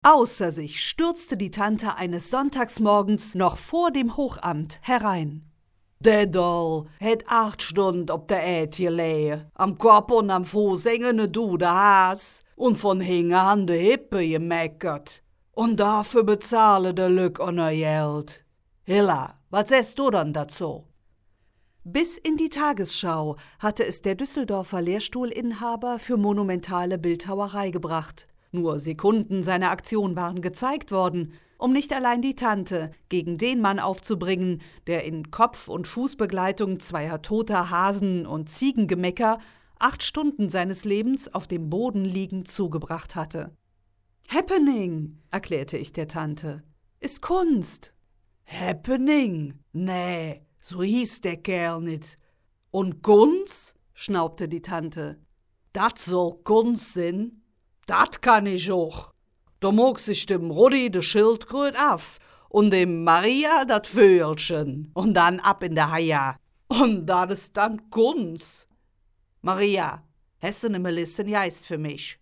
Profi-Stimme, mittleres Alter, Stimmlage Alt, Imagefilme, Werbespots, Hörbücher, Reportagen, Hörspiele, Sachtexte, PC-Spiele, E-Learning, Podcasts, Zeichentrick, Dialekte, Dokumentationen, Synchronisation, Features, Telefonansagen, Hotlines, Kölsch, slawischer Akzent, Mini-Studio, Formate .wav, .mp3
Sprechprobe: Sonstiges (Muttersprache):
female, middle-aged voice over talent